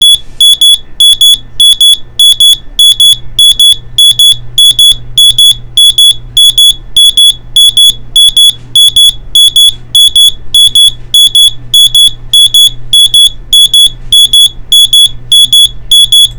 中音量タイプ 　ピッ・ピッ音　　音圧　９０ｄB/30cm
SPCM_pi_pi.wav